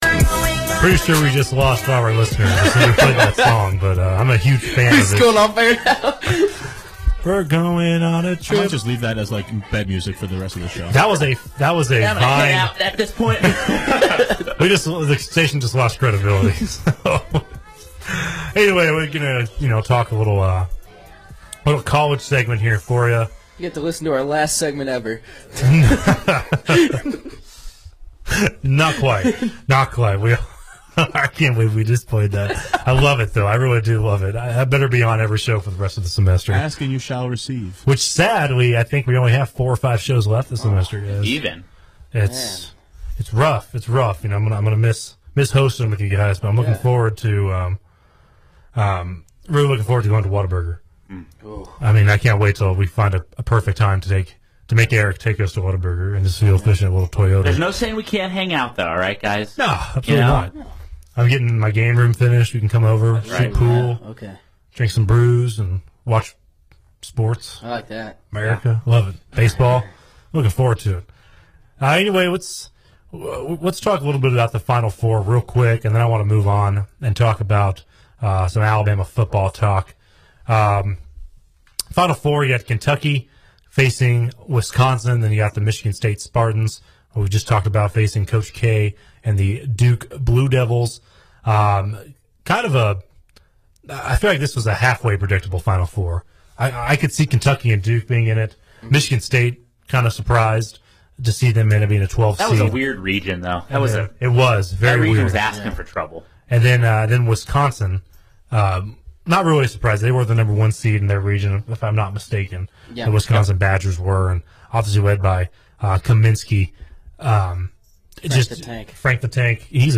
WVUA-FM's flagship sports talk show: The Student Section